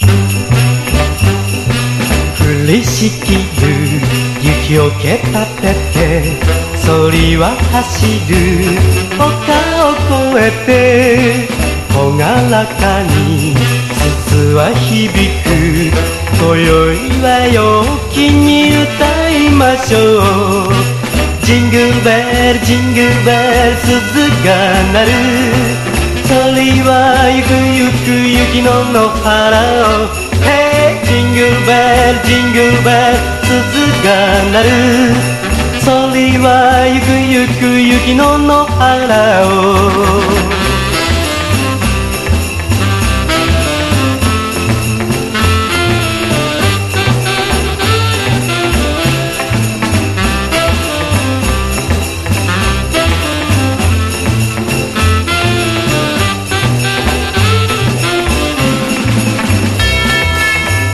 JAPANESE / 80'S / CITY POP / JAPANESE SOUL
ジャパニーズ・アーバン・ソウル名盤！